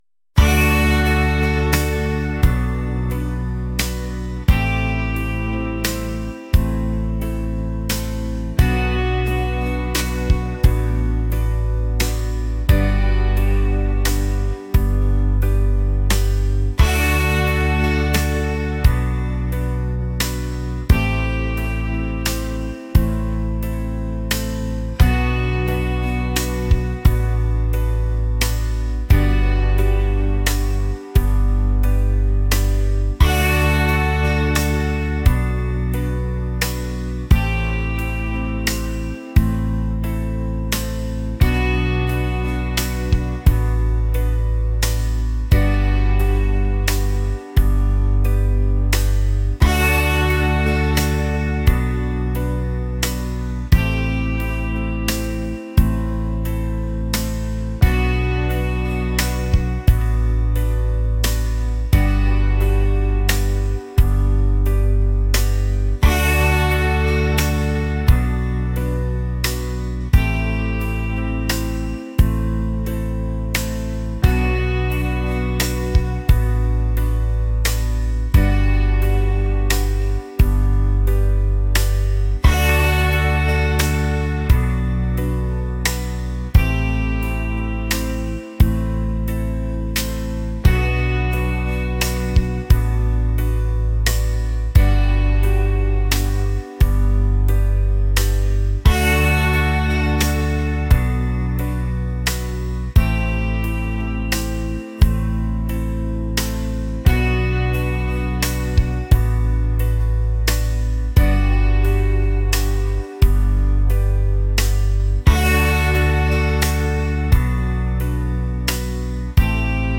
rock | smooth | mellow